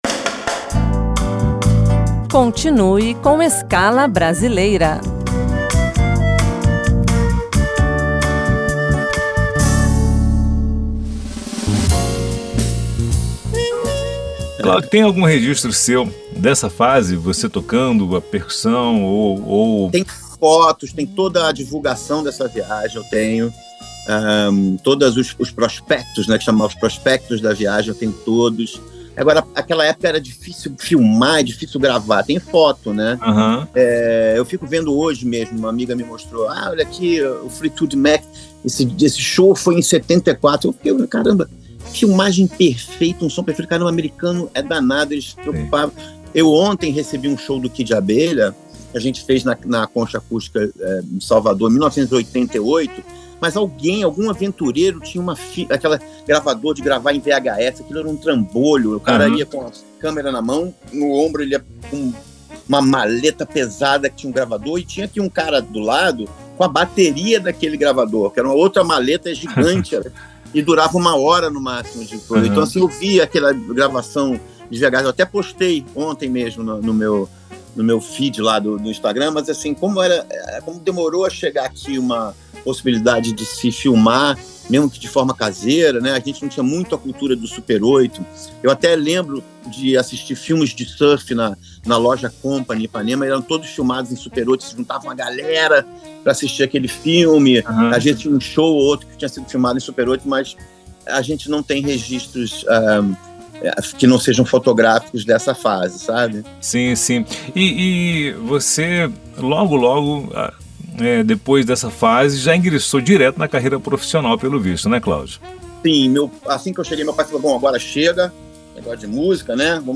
Escala Brasileira